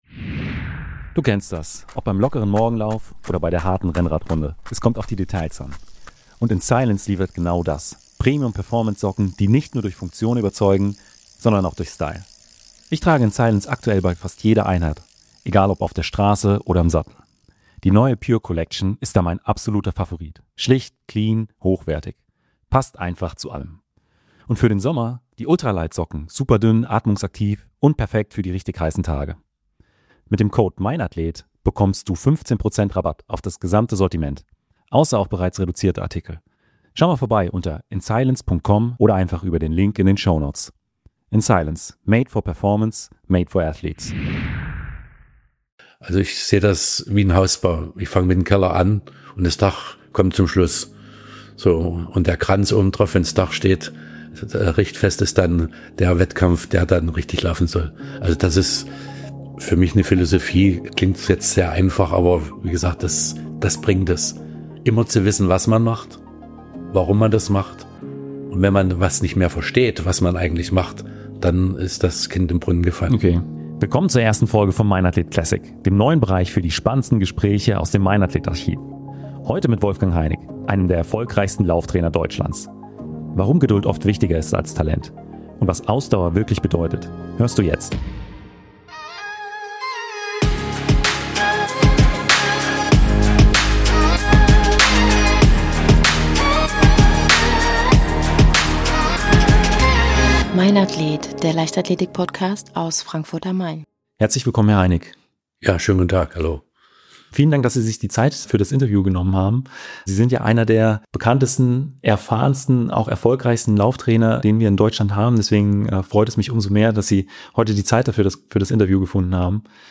Ich werde regelmäßig aktive und ehemalige deutsche Leichtathleten und Leichtathletinnen interviewen.